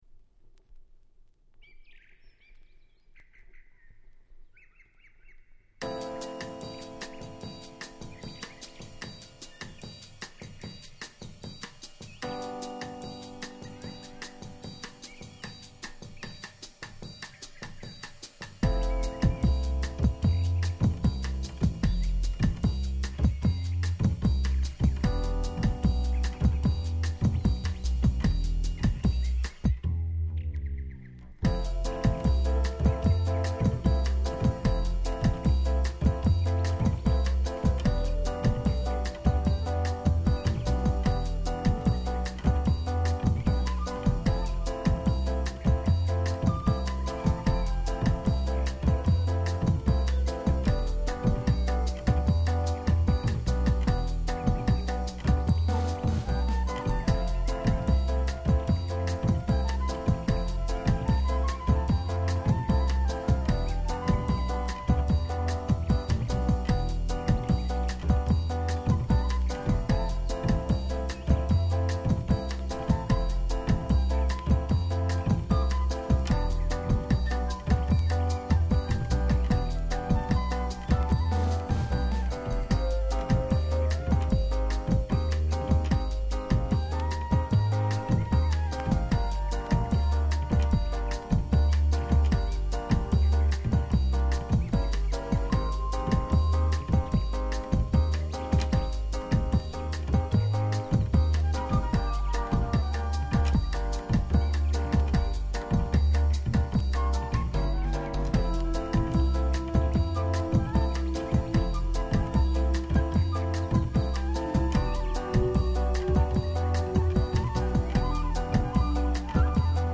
Bossa調からClub Jazzまで本当に良曲揃い！！